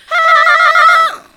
SCREAM11  -R.wav